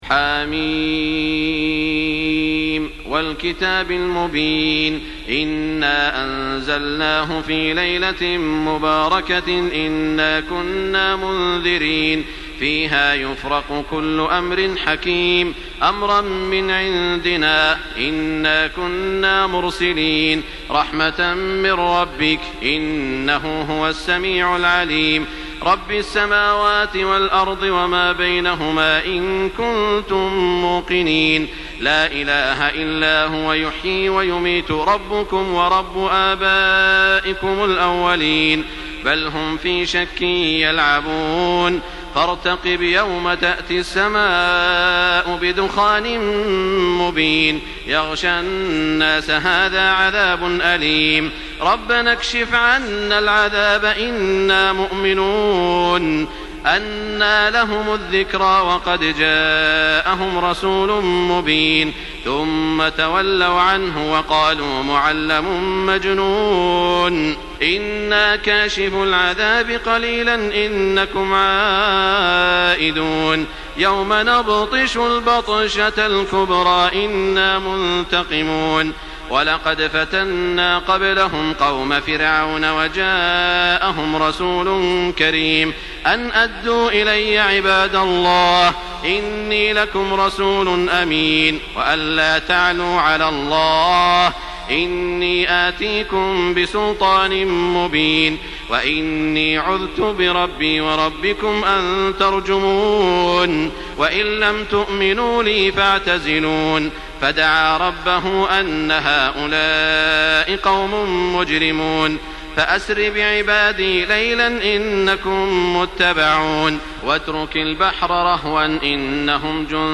Surah Ad-Dukhan MP3 in the Voice of Makkah Taraweeh 1424 in Hafs Narration
Surah Ad-Dukhan MP3 by Makkah Taraweeh 1424 in Hafs An Asim narration.
Murattal Hafs An Asim